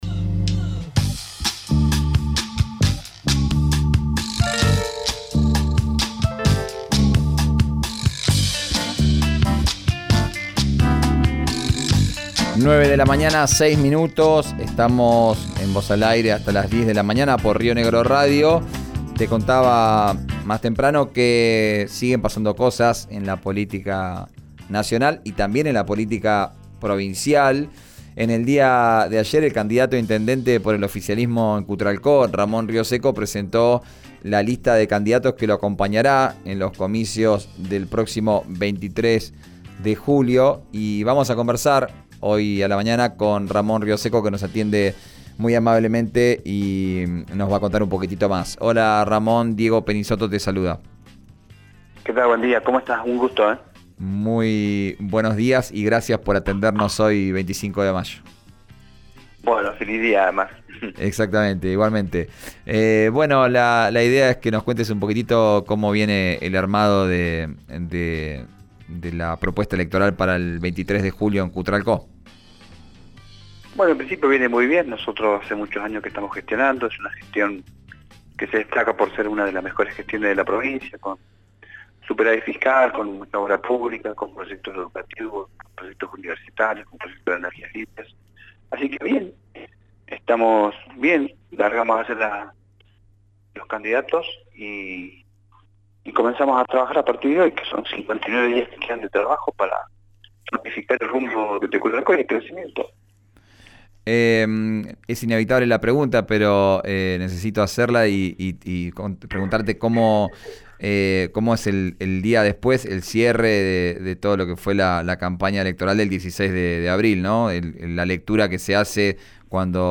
La presentación fue en el salón Arnoldo Janssen de Cutral Co